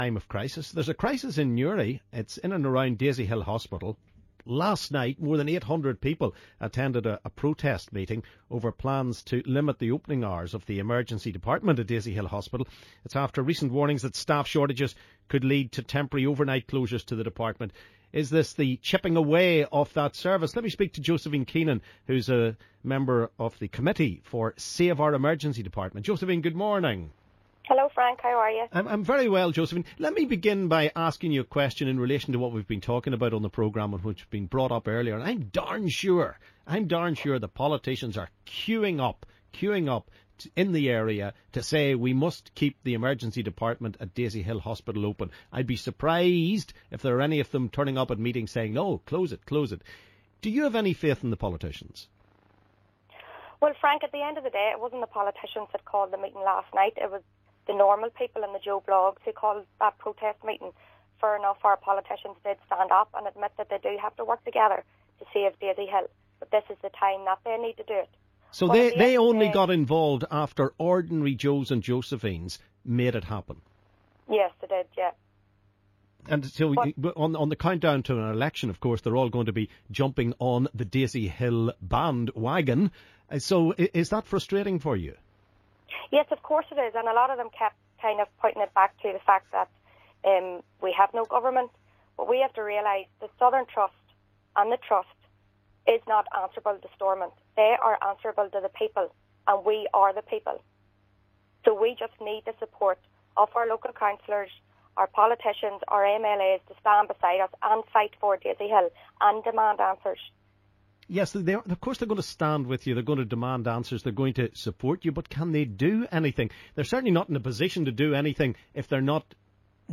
LISTEN: Huge protest meeting against A&E opening hour reductions at Daisy Hill Hospital